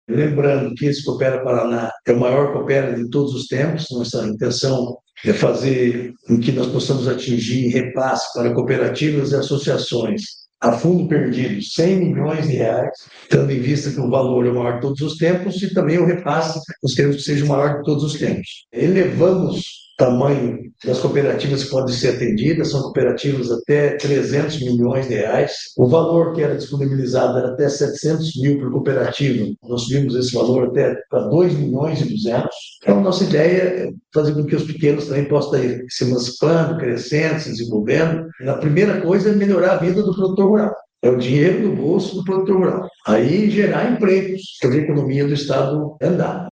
Sonora do secretário da Agricultura e do Abastecimento, Marcio Nunes, sobre o edital de R$ 100 milhões do Coopera Paraná